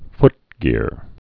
(ftgîr)